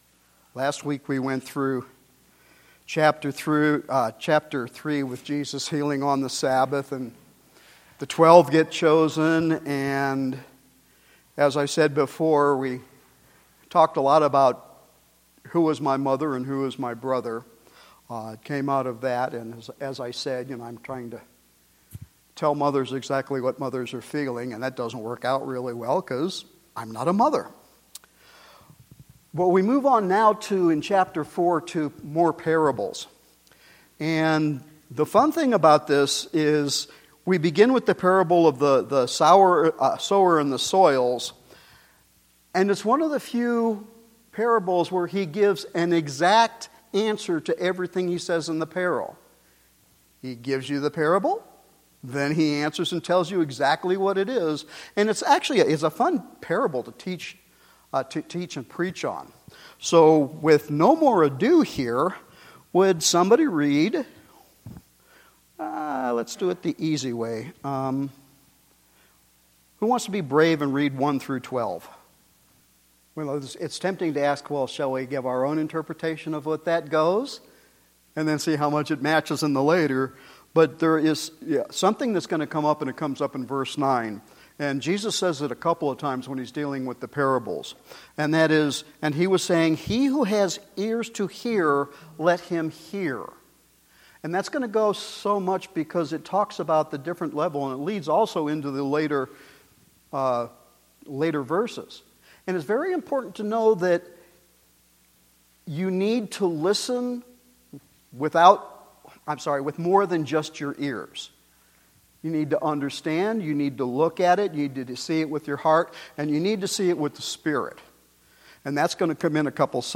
Our new Sunday evening Bible study continues with Mark Chapter 4.